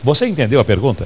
Silvio Santos perguntando ao participante do Show do Milhão se ele entendeu a pergunta do jogo.